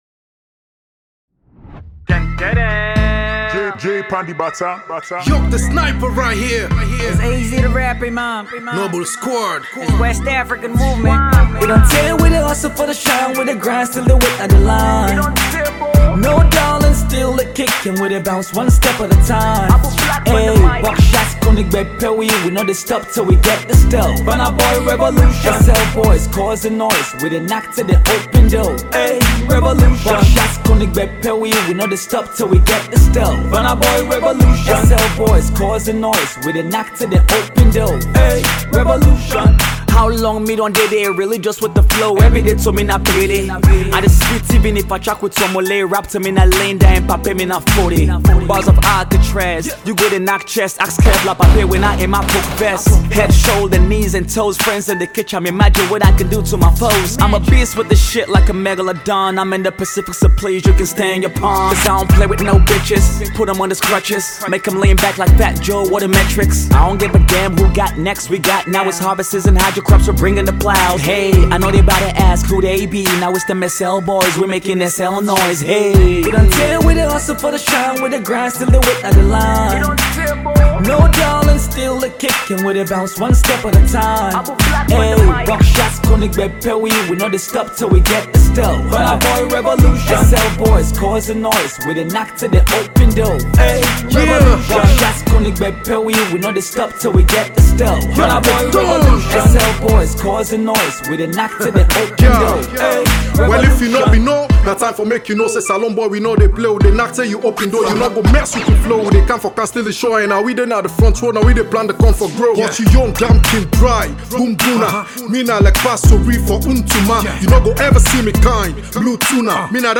Sierra Leonean rapper
a classic Hip Hop song